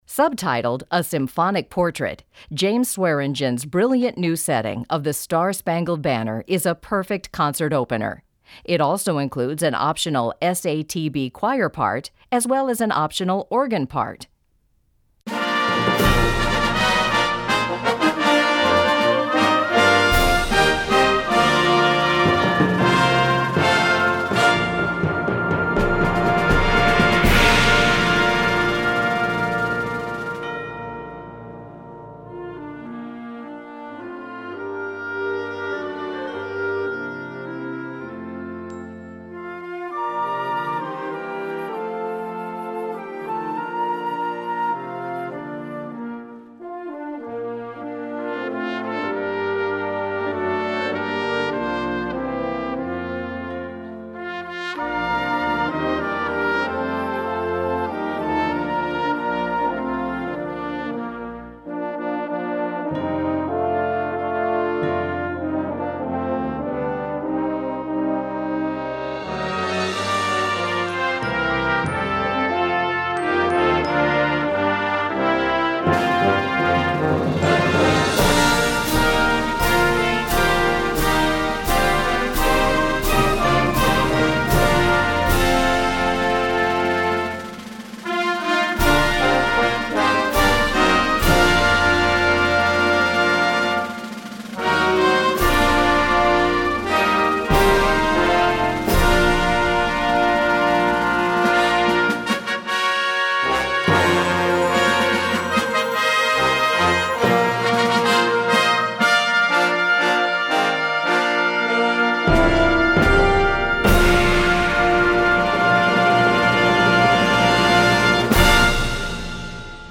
Gattung: Hymne
Besetzung: Blasorchester